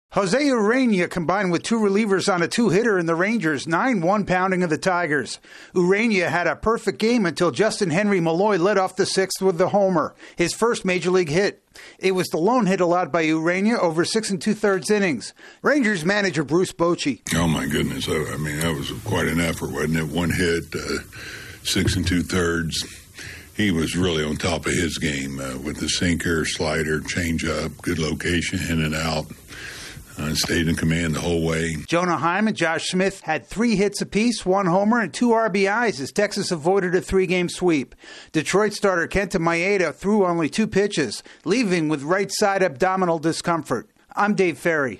A Rangers pitcher flirts with history in a rout of the Tigers. AP correspondent